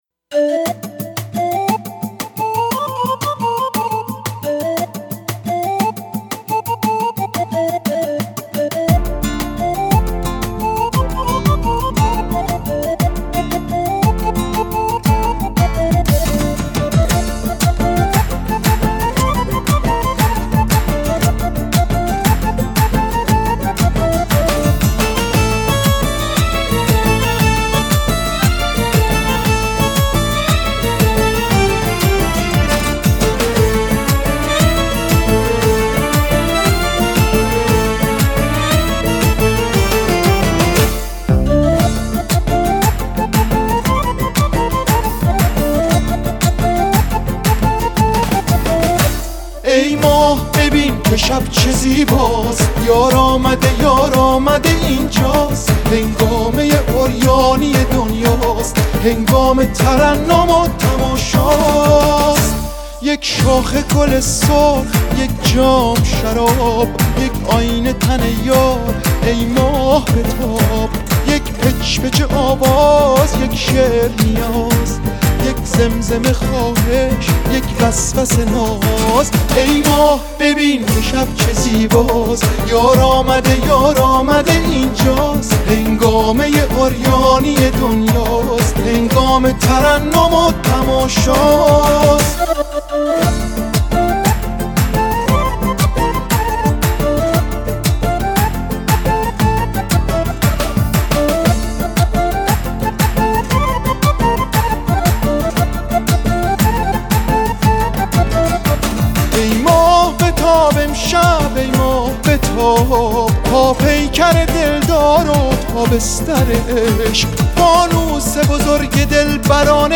ترکیبی از موسیقی سنتی ایرانی و سازهای مدرن
ملودی‌های احساسی و ریتم‌های پرانرژی